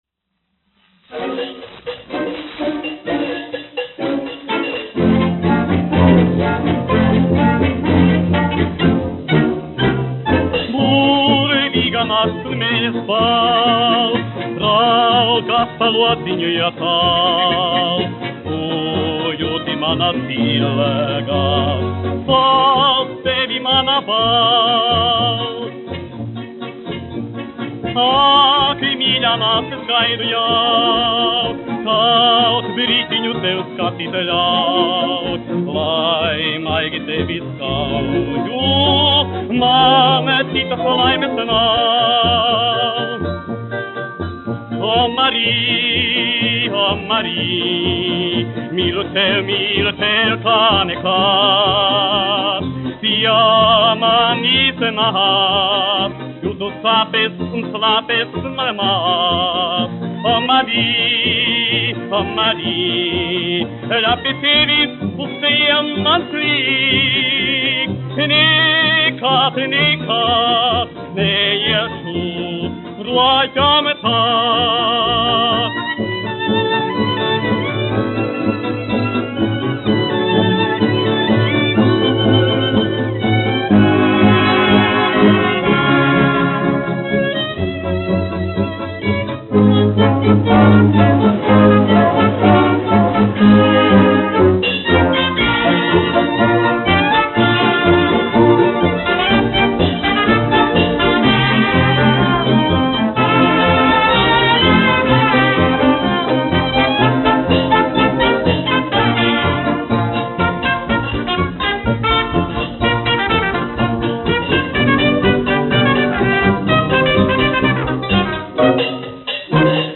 1 skpl. : analogs, 78 apgr/min, mono ; 25 cm
Populārā mūzika
Dziesmas, neapoliešu
Latvijas vēsturiskie šellaka skaņuplašu ieraksti (Kolekcija)